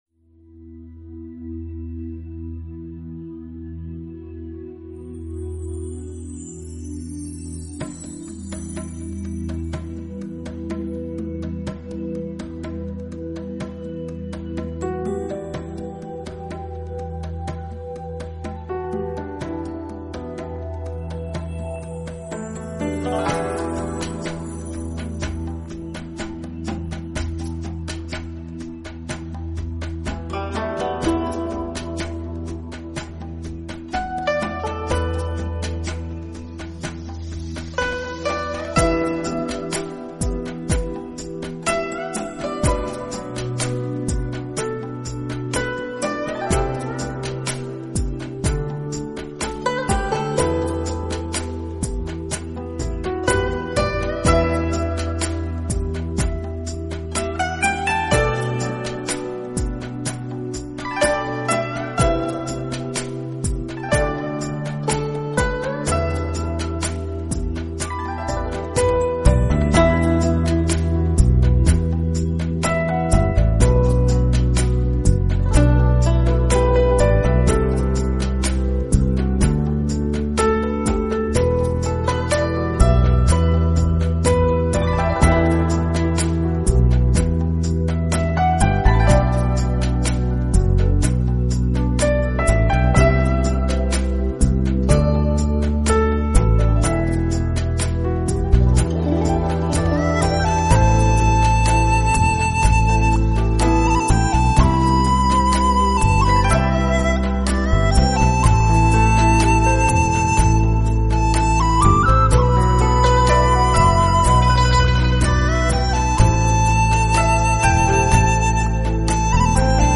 佛音 冥想 佛教音乐